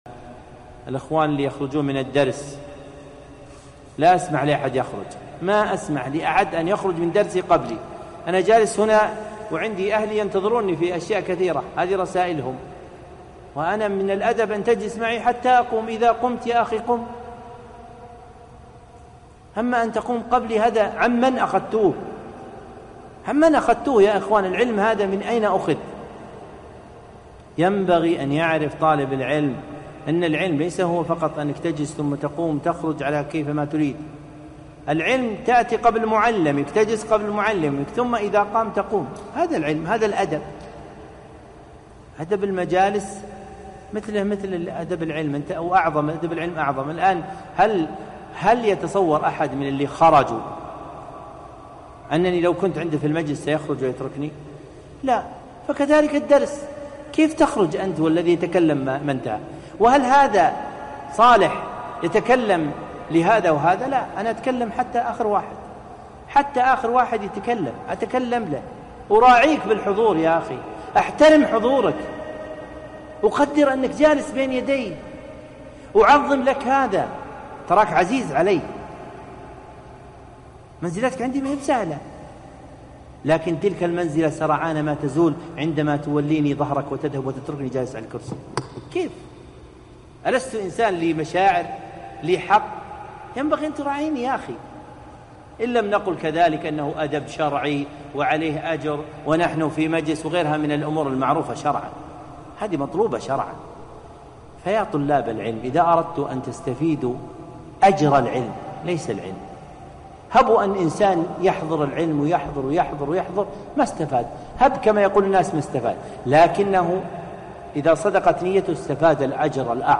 موعظة نافعة